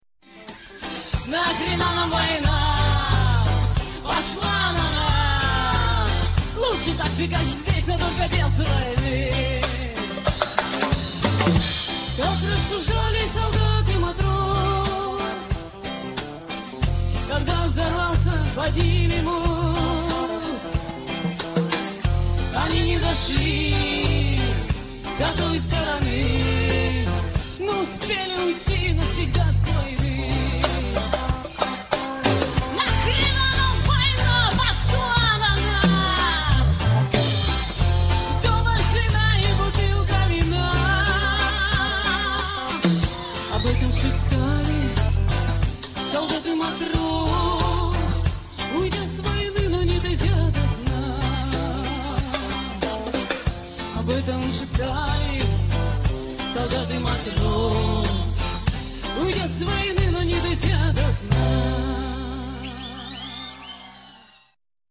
Концерт на Шаболовке (1995)
написанные в стиле регги
фрагмент песни ( 1 мин.)